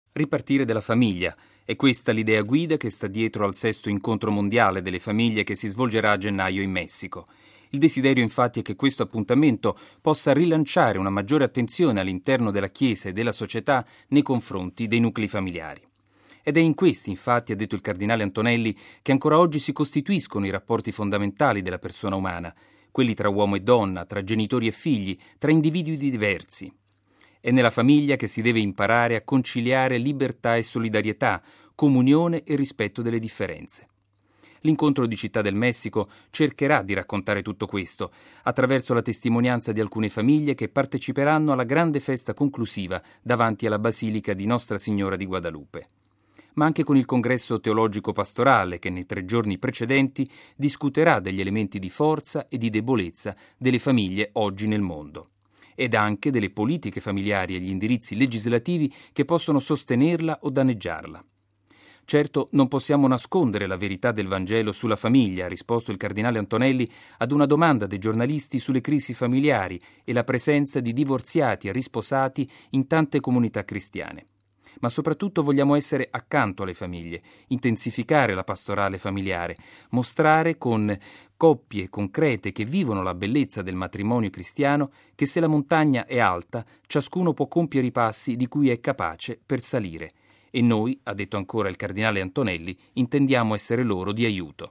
◊   La preparazione al prossimo Incontro mondiale delle famiglie, che si terrà a Città del Messico dal 16 al 18 gennaio 2009, è stata oggi illustrata ai giornalisti presso il Pontificio Consiglio per la famiglia.